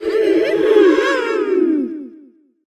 tara_start_vo_01.ogg